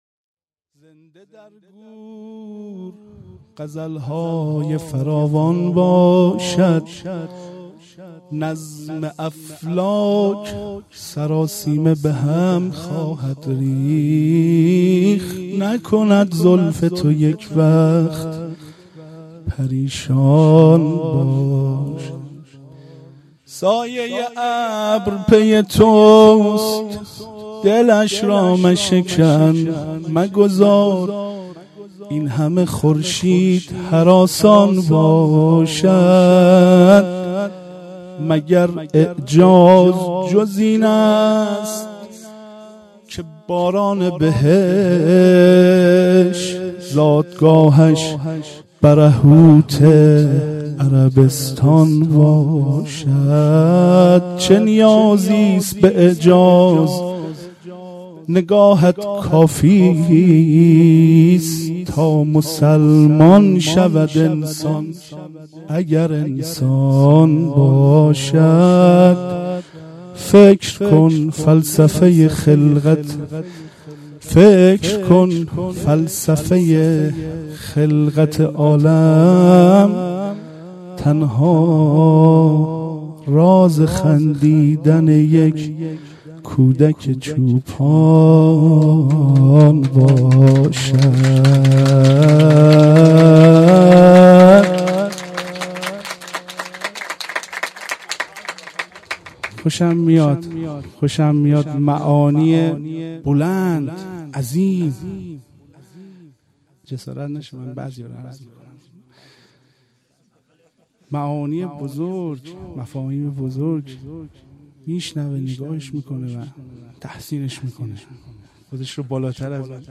جشن میلاد حضرت رسول (ص) و امام جعفر صادق (ع) 1395
شعر «2»